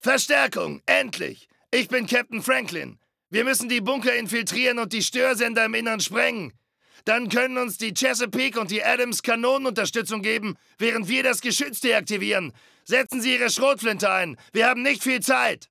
The texts and VO are completely localised in German.